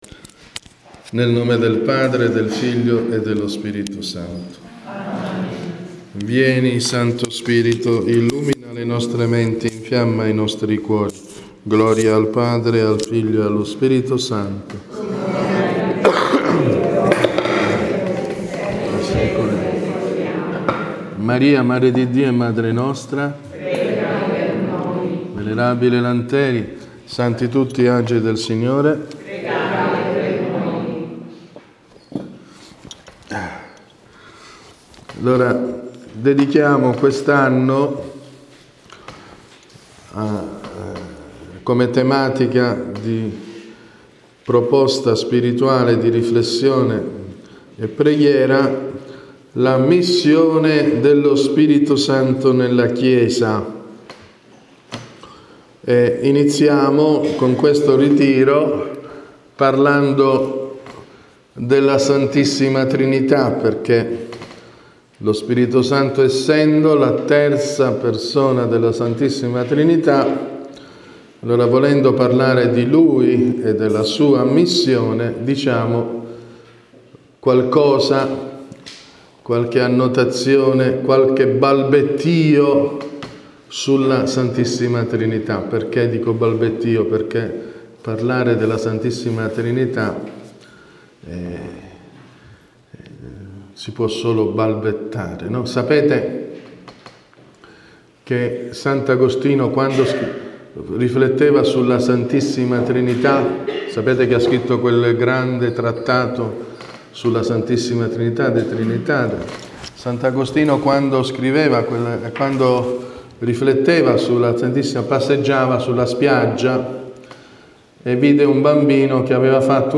Conferenza spirituale